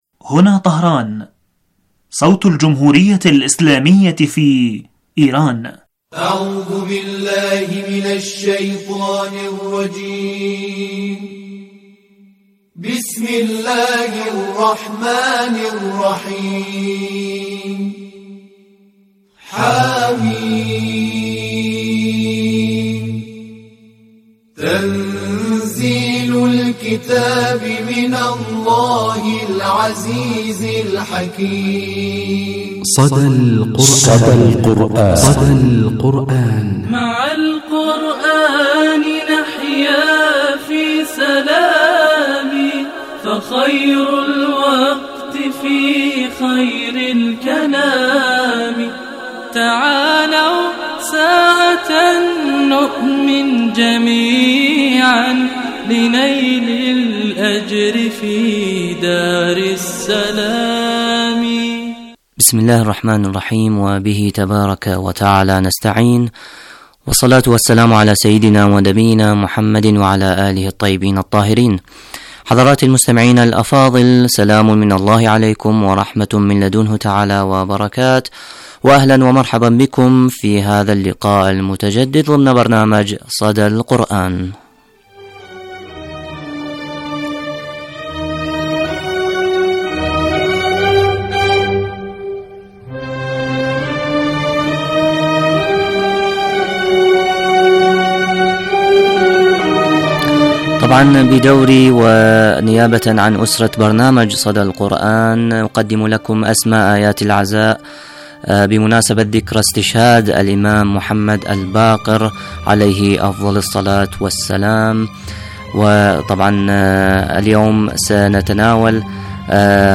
مجلة قرآنية أسبوعية تتناول النشاطات القرآنية ومشاهير القراء من الرعيل الأول ولقاءات وأسئلة قرآنية ومشاركات المستمعين وغيرها من الفقرات المنوعة.